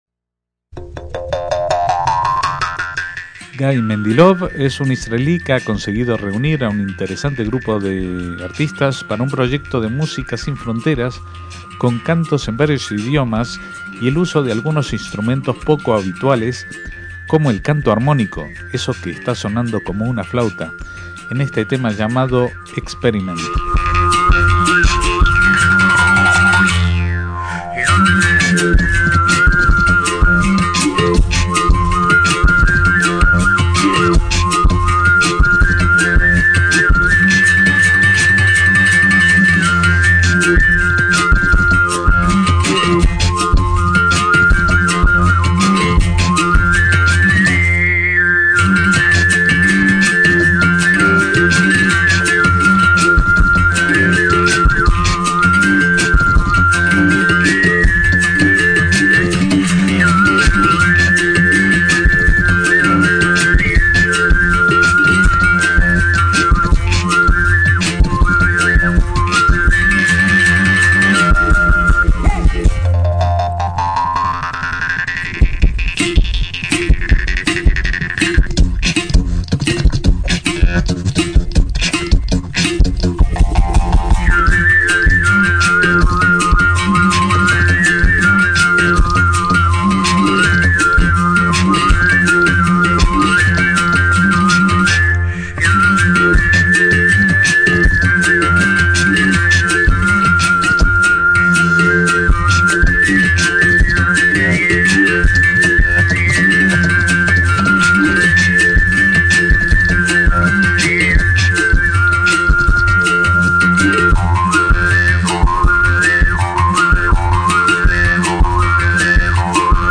es un conjunto instrumental de músicas del mundo
clarinete, saxo e instrumentos étnicos
violín, acordeón, guitarra
voz, guitarra, mandolina, oud